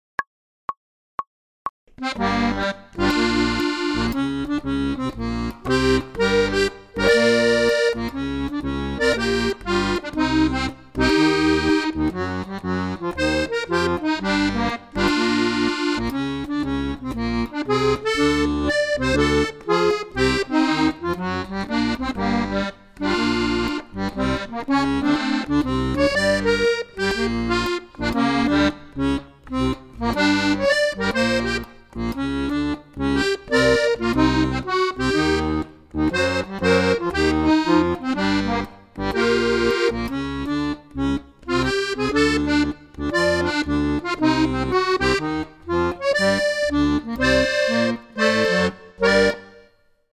DIGITAL SHEET MUSIC - ACCORDION SOLO